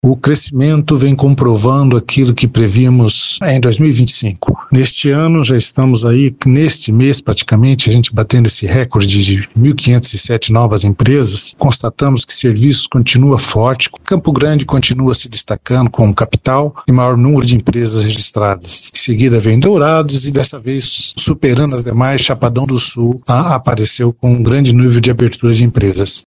O presidente da JUCEMS, Nivaldo Gomes da Rocha, em entrevista à FM Educativa MS, destaca que o setor de serviços continua liderando a criação de novos negócios no estado.